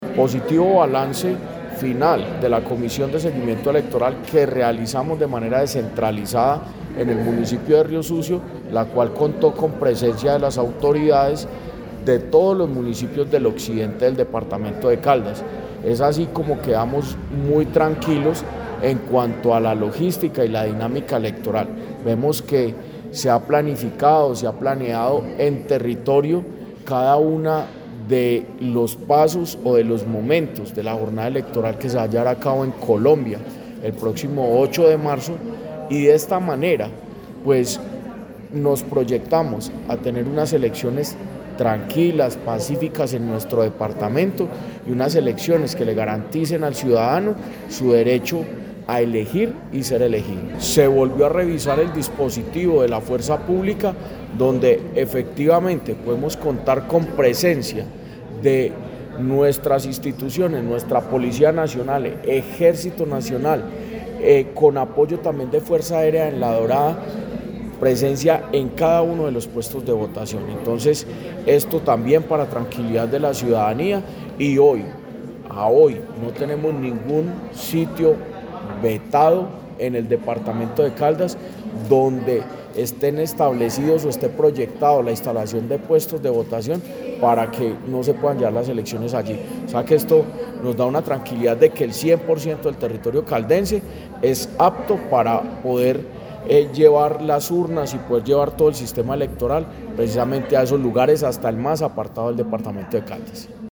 Jorge Andrés Gómez Escudero, secretario de Gobierno de Caldas.